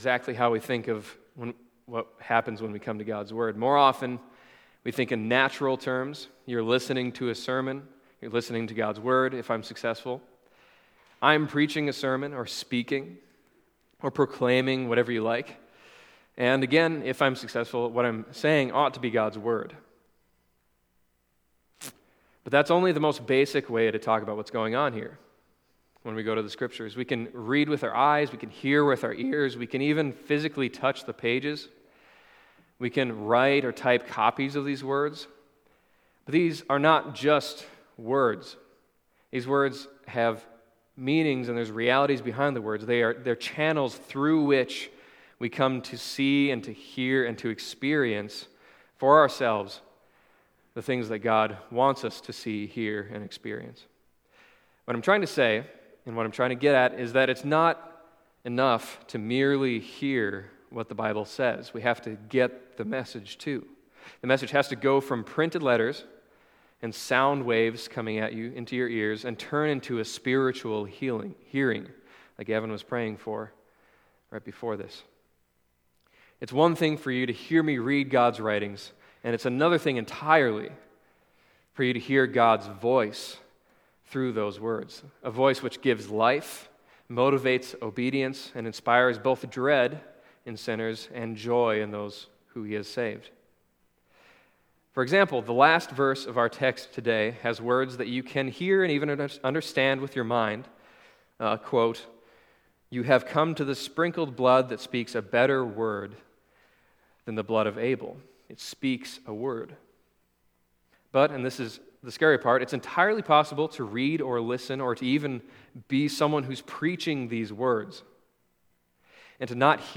Other Passage: Hebrews 12:18-24 Service Type: Sunday Morning Hebrews 12:18-24 « The Wrath of God Revealed